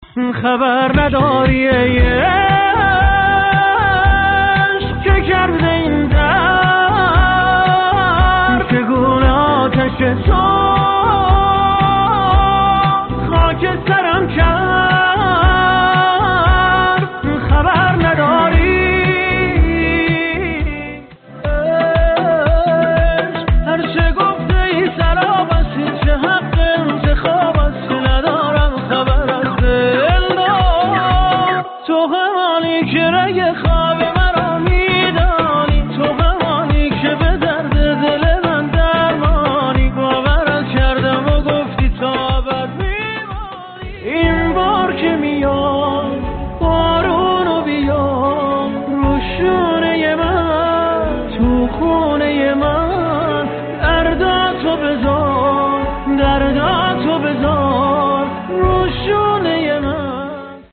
گلچین ریمیکس پشت سر هم آهنگ های